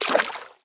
sfx_walk_water_0.mp3